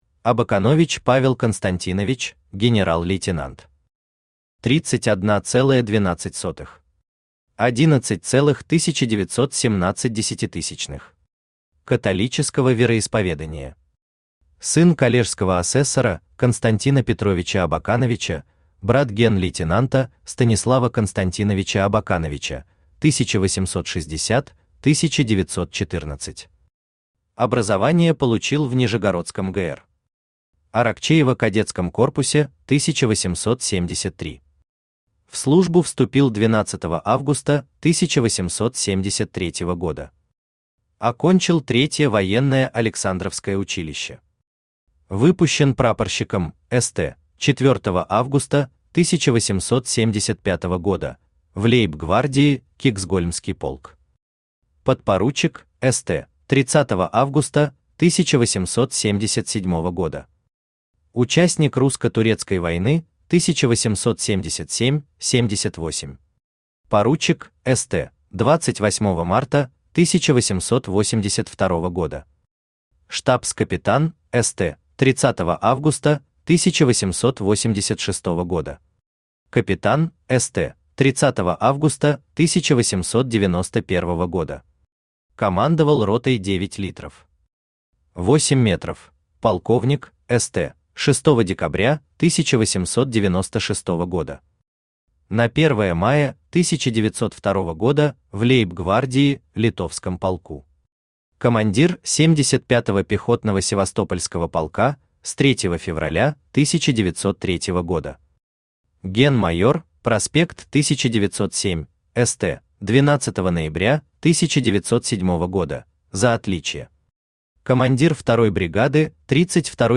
Аудиокнига Генералы Русской Императорской Армии 1914–1917 гг. Том 1 | Библиотека аудиокниг
Том 1 Автор Денис Соловьев Читает аудиокнигу Авточтец ЛитРес.